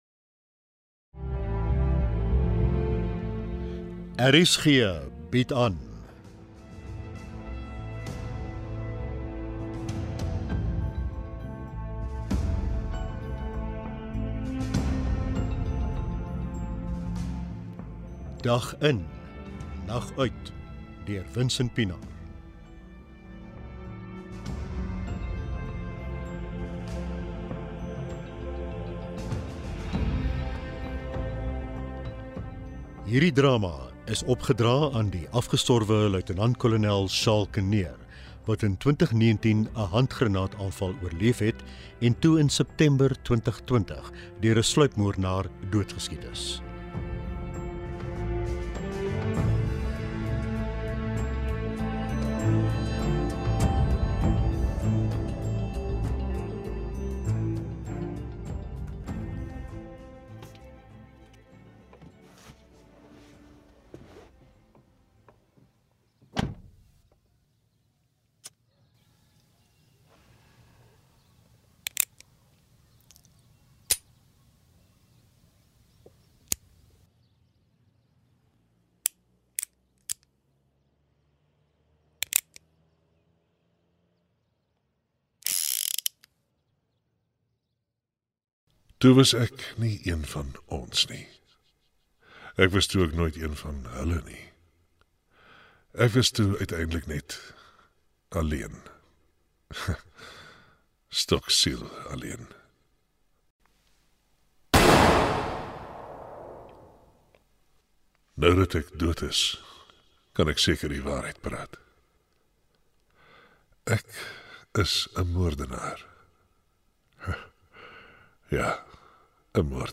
Hierdie drama vertel presies wat gebeur het, en dis op ‘n ware verhaal gebaseer.